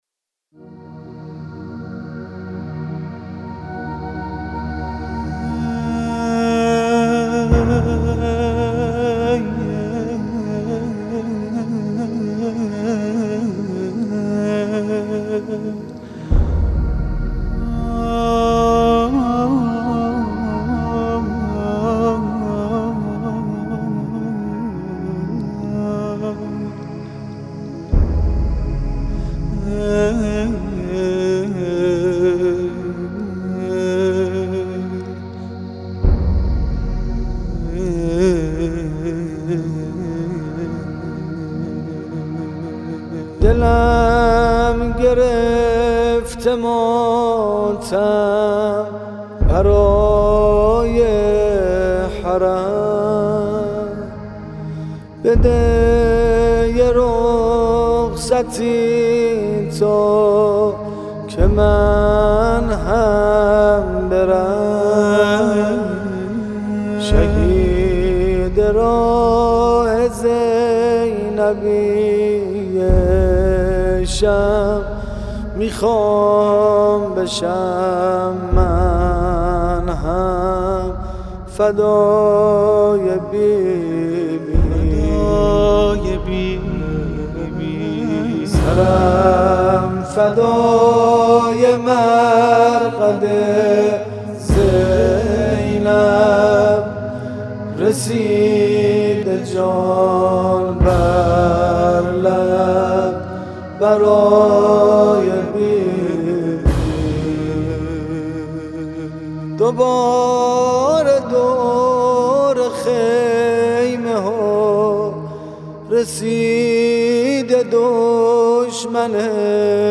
خواننده ارزشی شهر قرچک، سروده‌ای به نام